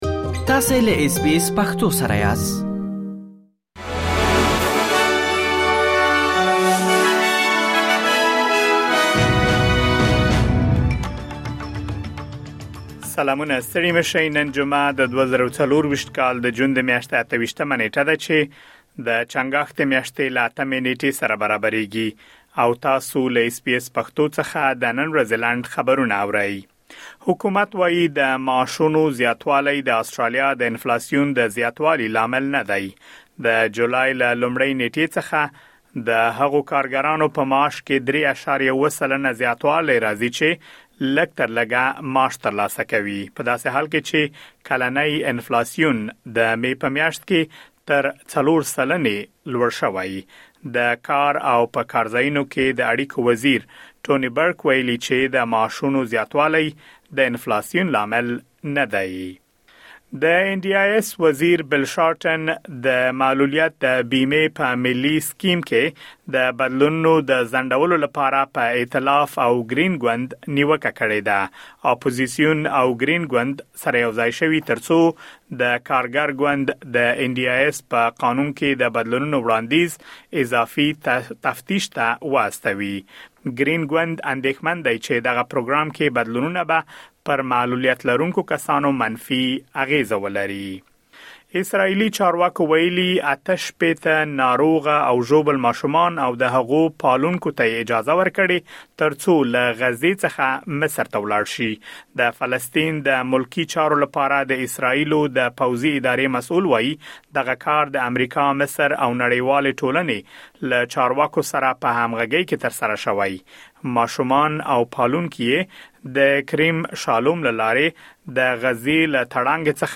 د اس بي اس پښتو د نن ورځې لنډ خبرونه|۲۸ جون ۲۰۲۴
د اس بي اس پښتو د نن ورځې لنډ خبرونه دلته واورئ.